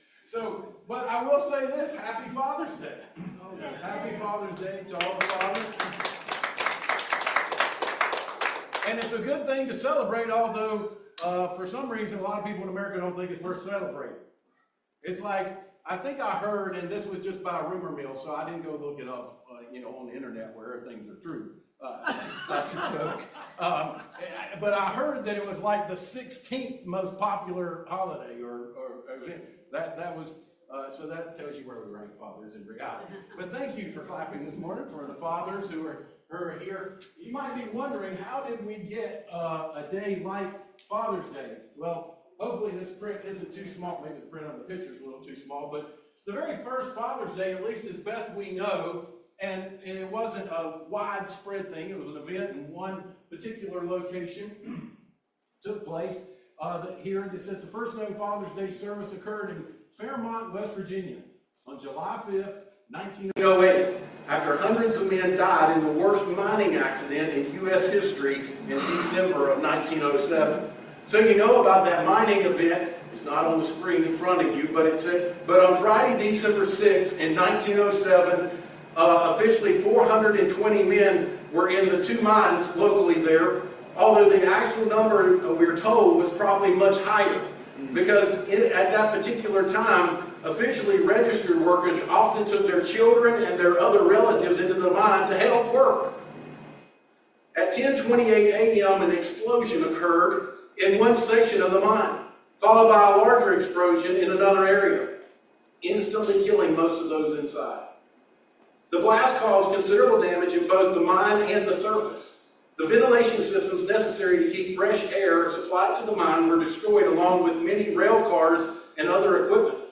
Psalm 68:5 Service Type: Family Bible Hour Fathers should represent God in the home.